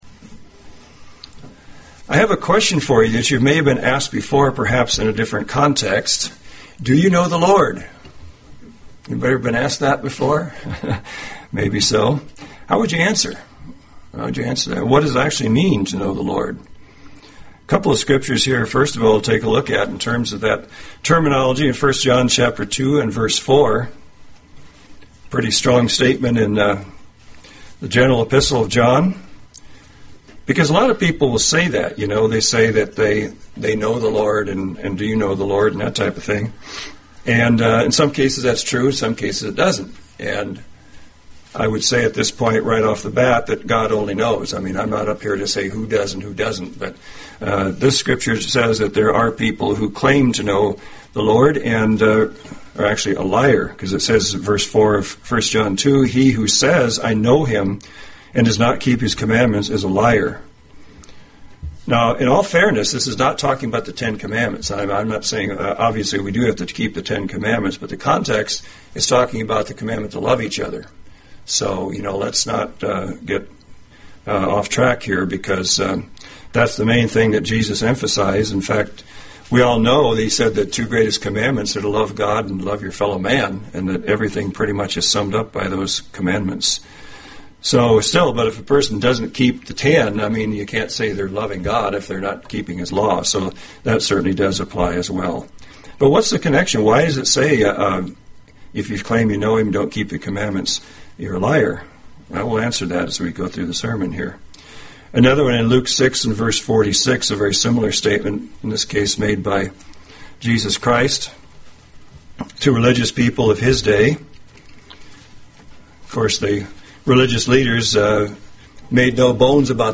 UCG Sermon Notes Bend 7-31-10 Do You Know the Lord?